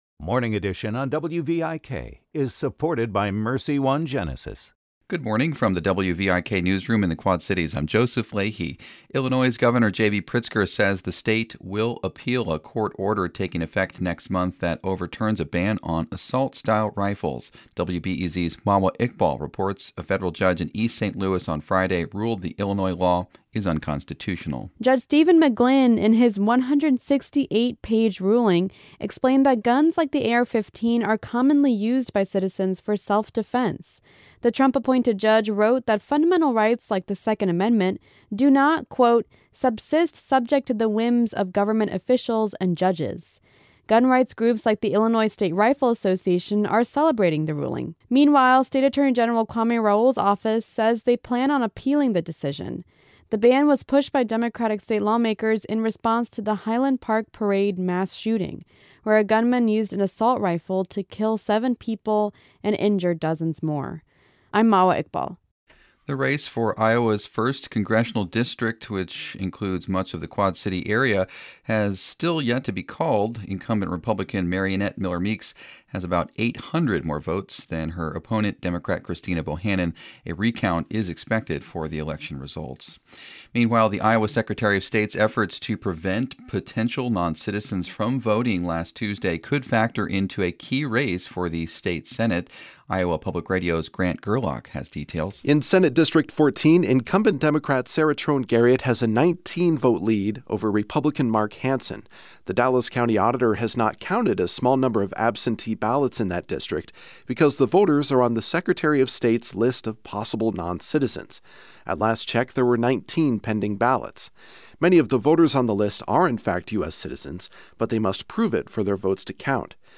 Morning headlines from WVIK News.